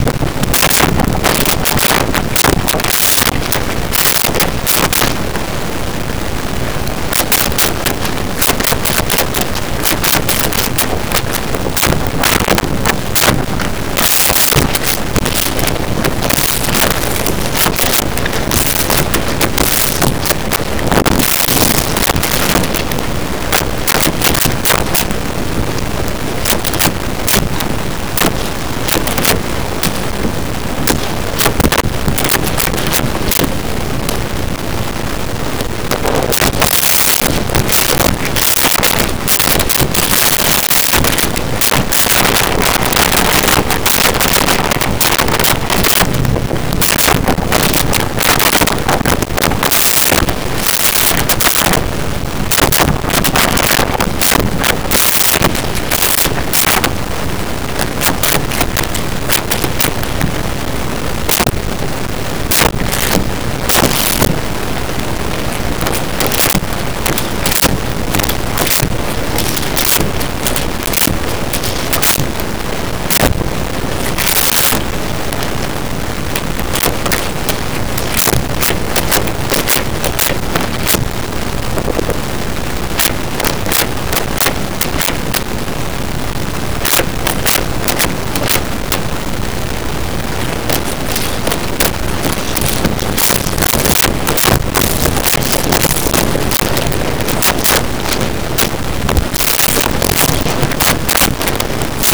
Card Shuffle And Deal
Card Shuffle And Deal.wav